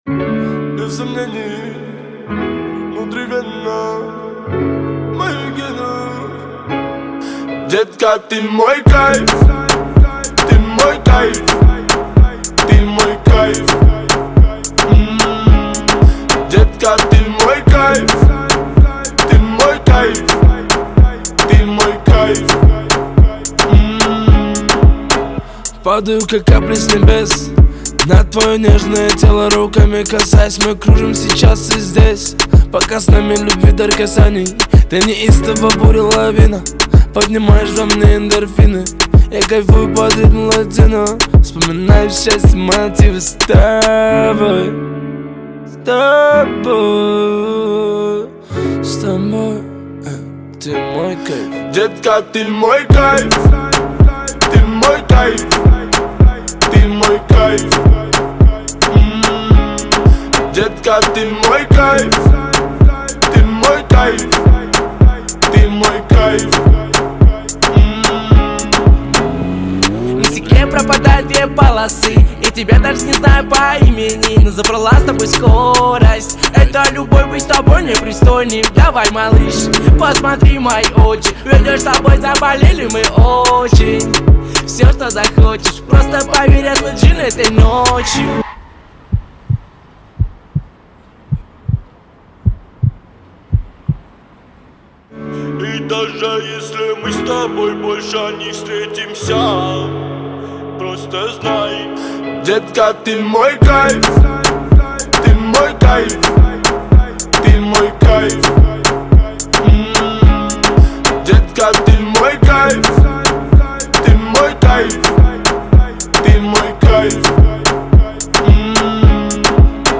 آهنگ روسی معروف اینستا
آهنگ خارجی بیس دار